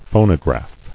[pho·no·graph]